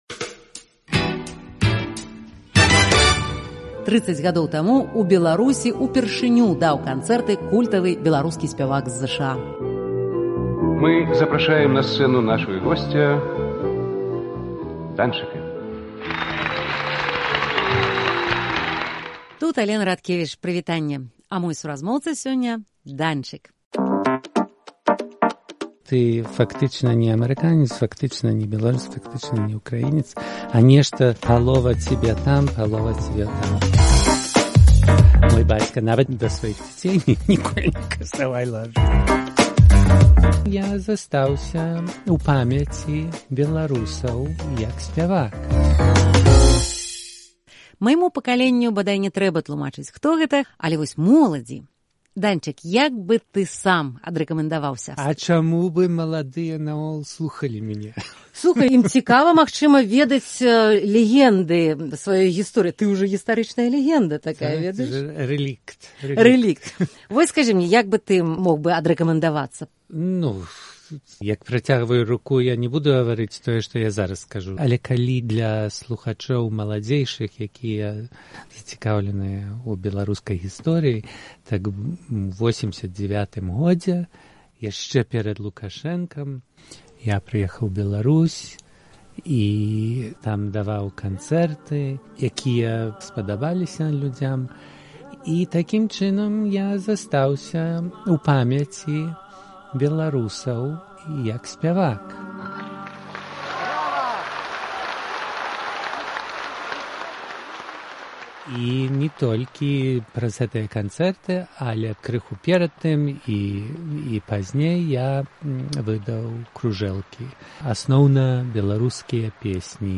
Новы падкаст-цыкль Свабоды - "Невядомы Данчык". Гутаркі зь легендарным беларускім сьпеваком з Амэрыкі, нашчадкам сям'і Луцкевічаў Багданам Андрусышыным.